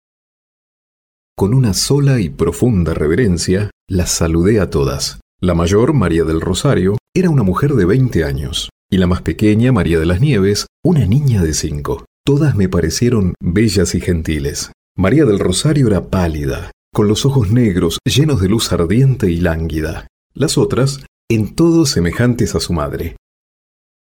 Locutor argentino.
locutor argentina, argentinian voice over